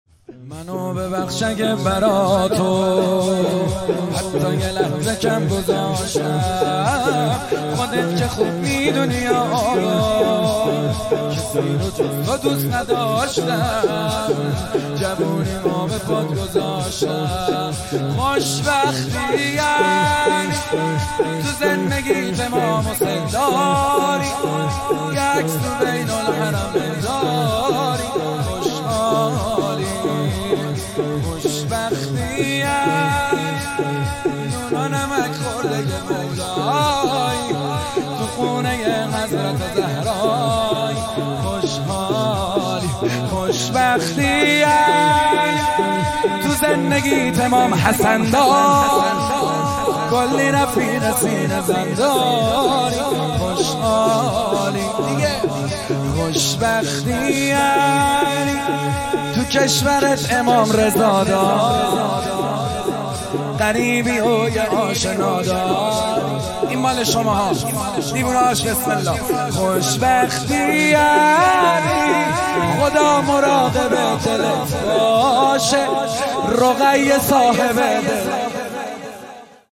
مداحی محمدحسین حدادیان |شهادت امام جواد (ع) | هیئت احمدی آستان سلطان امیر احمد | پلان 3